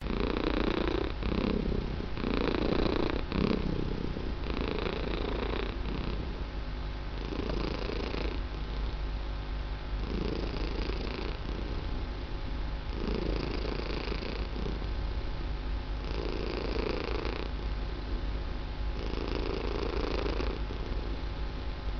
Звуки мурчания кошек
Мфррр